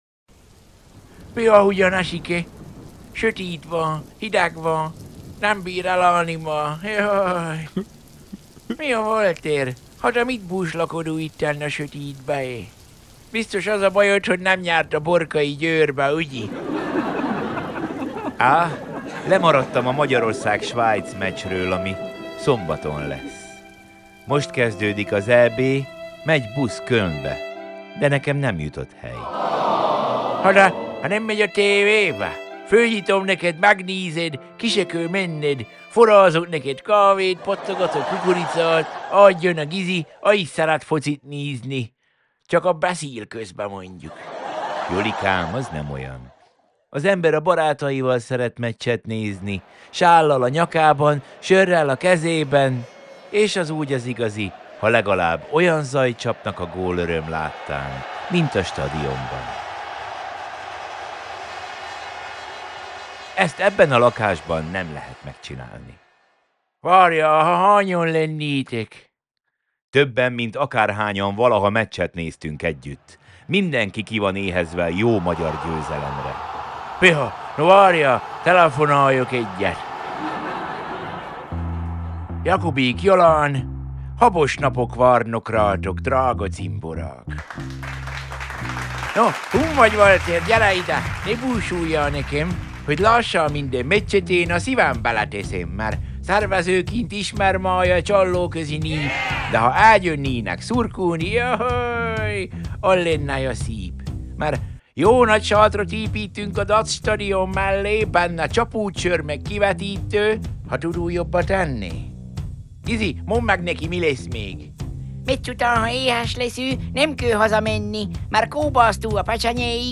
The White Stripes - Seven Nation Army (Instrumental)